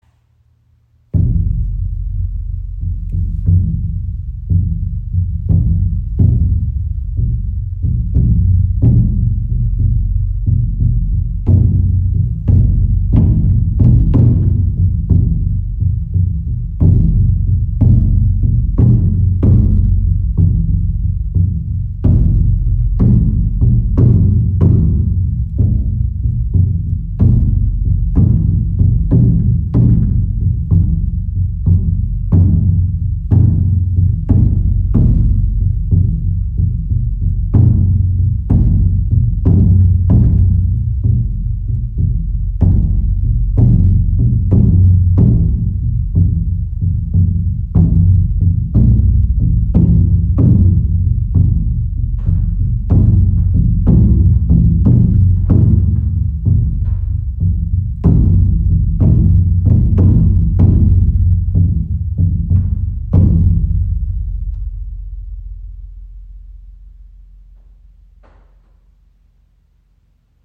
Mother Drum | ø 120 cm | Schamanisches Trommeln und Klangreisen
• Icon Tiefer, erdverbundener Klang – erinnert an den Herzschlag der Erde
Gefertigt aus edlem Mahagoniholz und mit sorgfältig ausgewählten Kuhhäuten bespannt, entfaltet sie auf ihrem traditionellen Wurzelholztisch einen weiten, tiefen Klang.